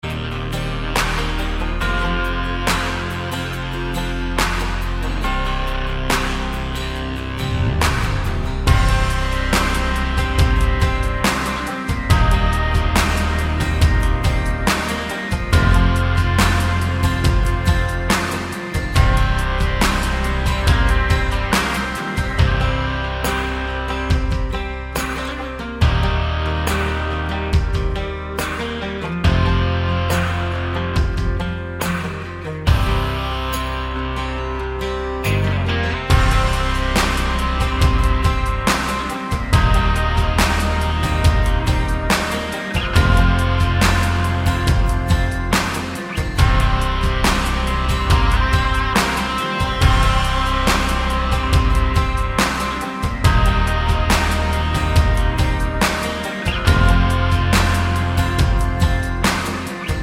no Backing Vocals Country (Male) 3:23 Buy £1.50